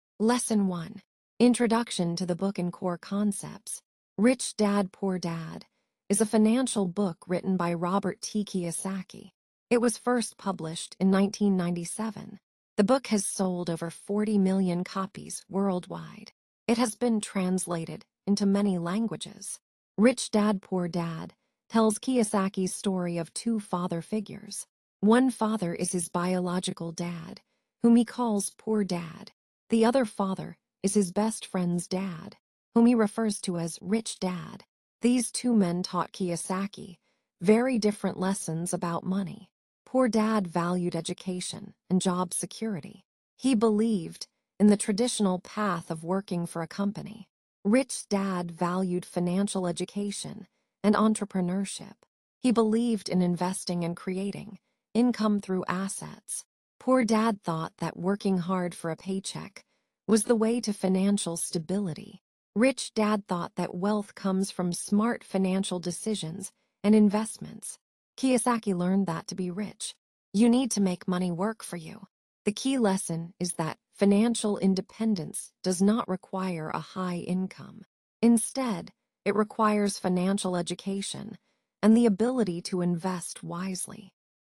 Rich-Dad-Poor-Dad-Resumo-Parte-1-mulher-3.mp3